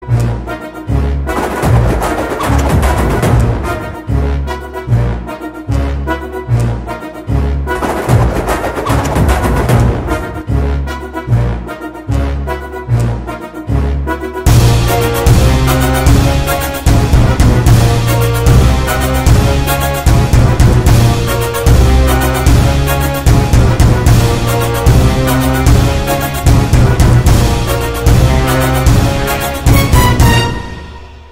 Blog, Telugu Ringtones 31 Sec    update 81 Views